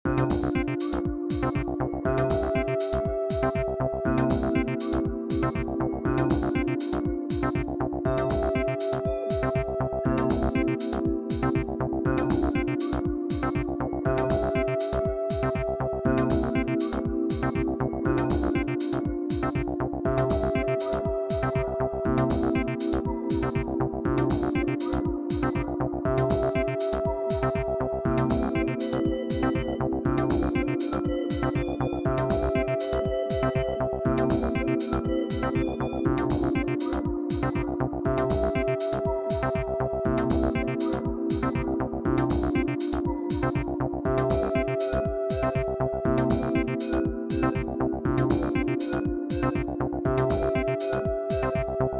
303 short loop 52s